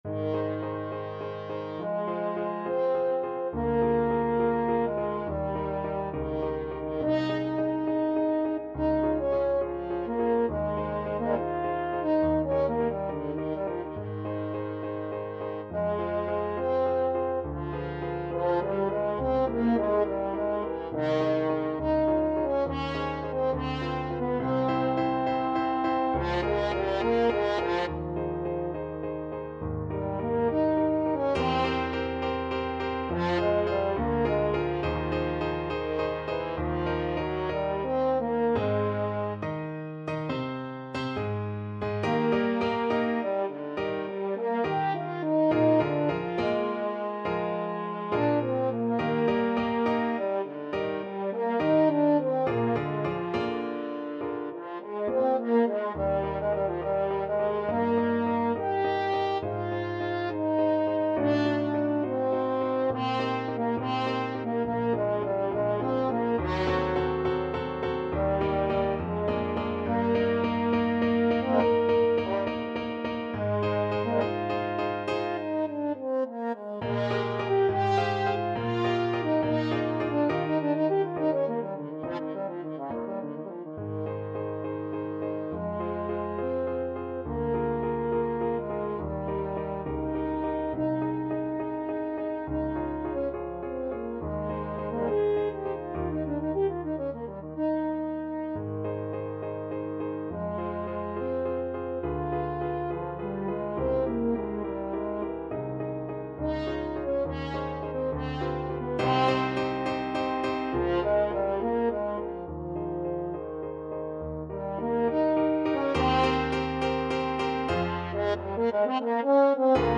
Free Sheet music for French Horn
Ab major (Sounding Pitch) Eb major (French Horn in F) (View more Ab major Music for French Horn )
4/4 (View more 4/4 Music)
Classical (View more Classical French Horn Music)